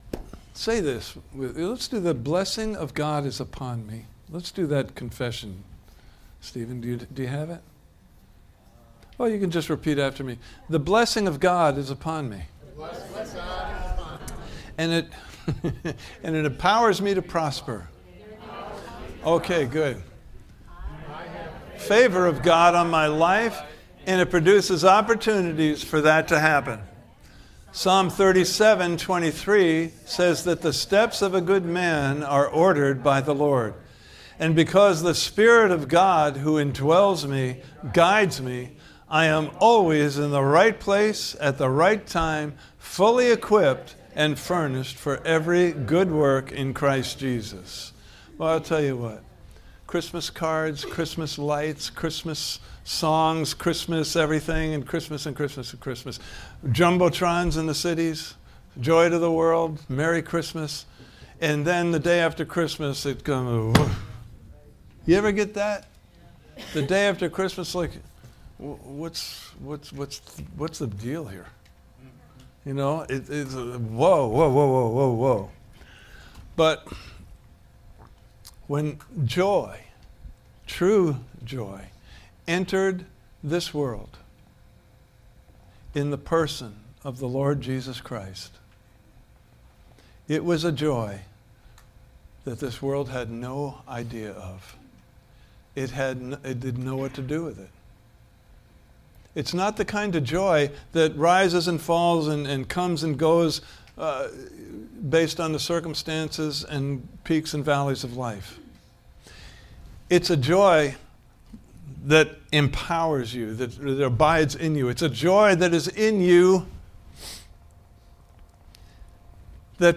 Series: The Wonderful Joy of the Lord! Service Type: Sunday Morning Service « Part 2: The Joy of the Lord is Fresh Oil!